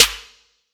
Snare SwaggedOut 8.wav